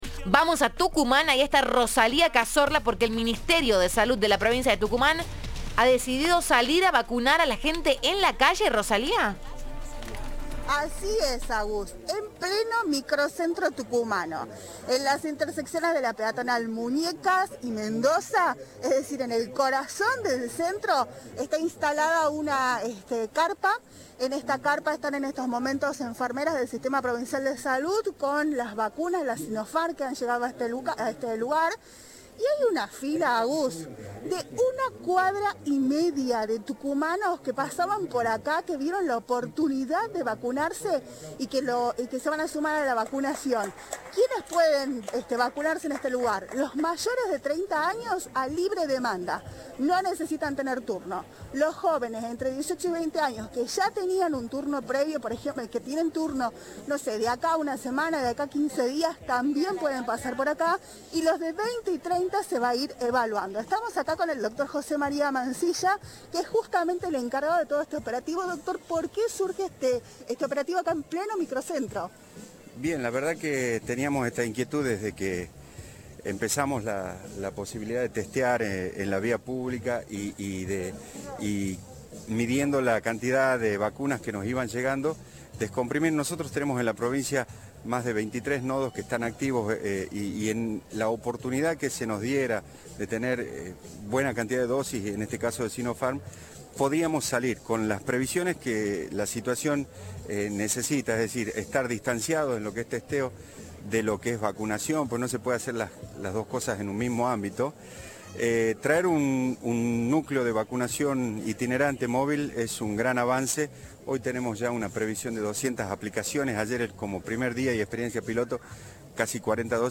Otra mujer expresó que su turno era en una sede que le quedaba muy lejos, por lo que decidió acercarse al centro y vacunarse allí.